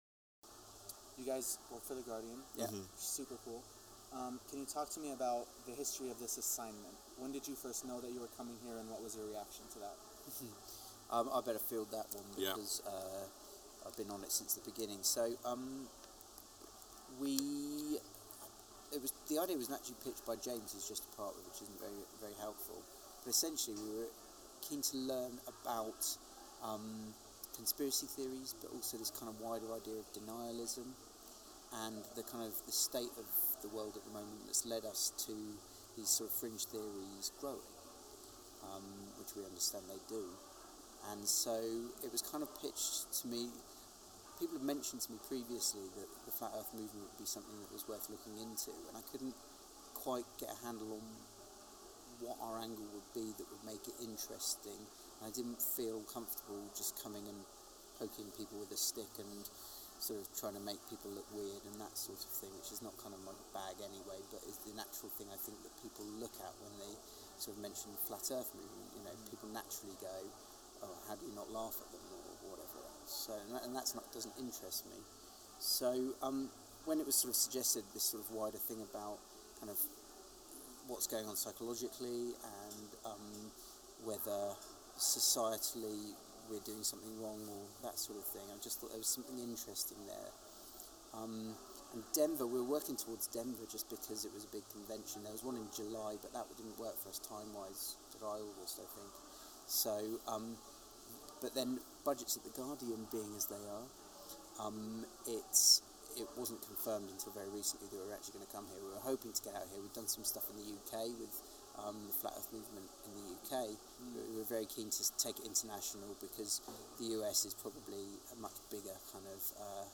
I sit down with two writers from the Guardian to discuss our impression of the flat earth conference in Denver, Colorado.
flat-earth-3-the-guardian-interview.mp3